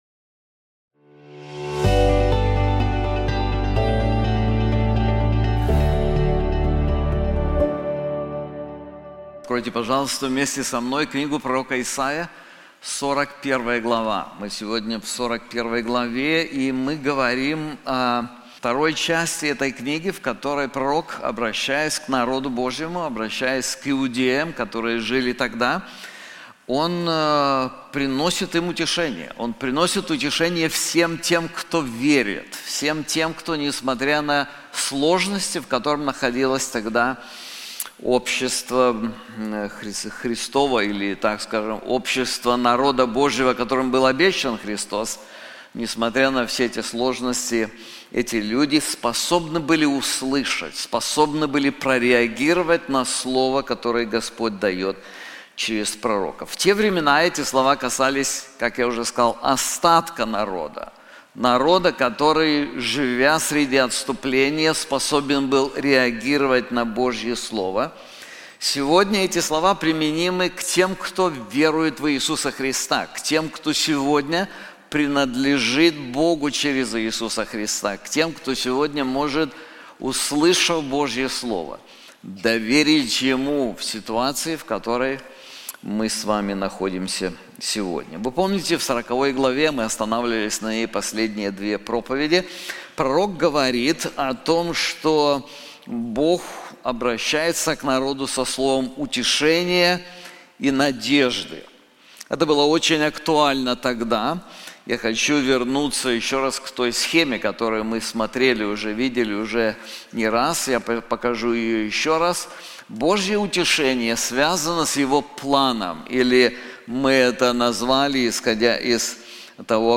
This sermon is also available in English:How to Overcome Fear • Isaiah 41:1-29